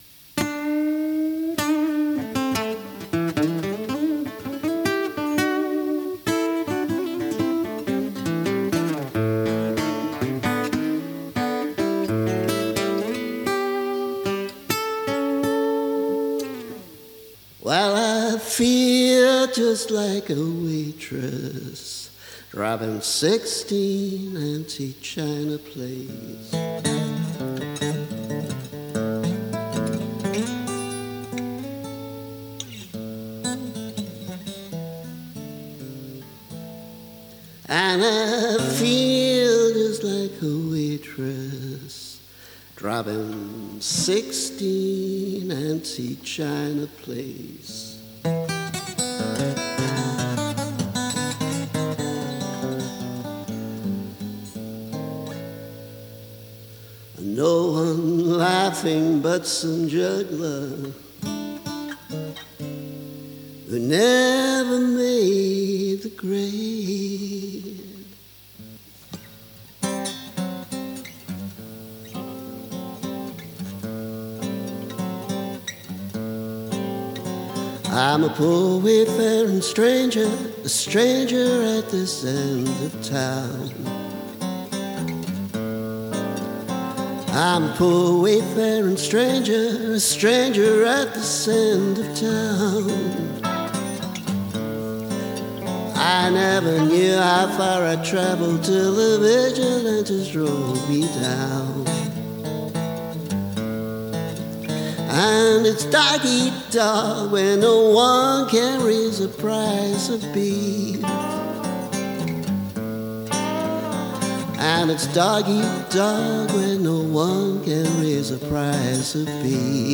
A sort of heavy metal protest song.
Just an acoustic guitar with an electric guitar overdubbed.